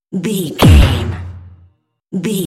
Dramatic hit deep electronic
Sound Effects
Atonal
heavy
intense
dark
aggressive
hits